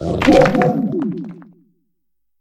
Cri de Tomberro dans Pokémon HOME.